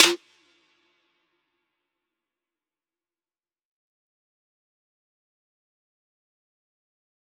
DMV3_Snare 15.wav